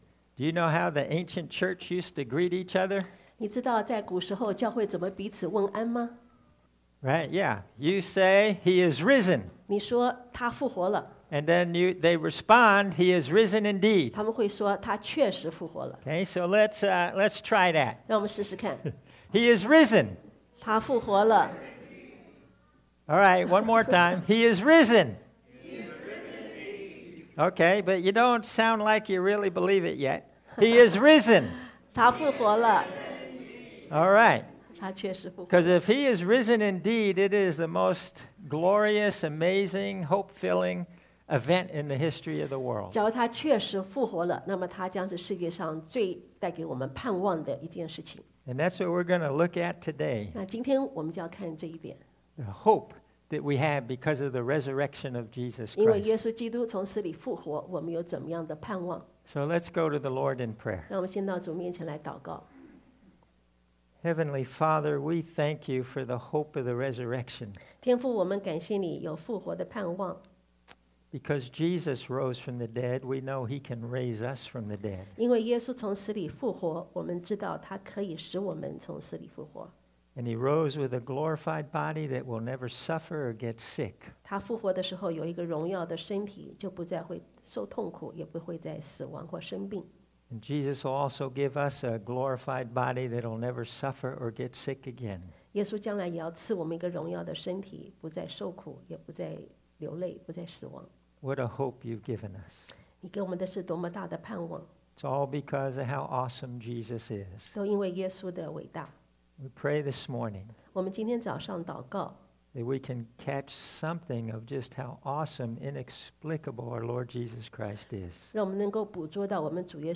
Passage: John 11 Service Type: Sunday AM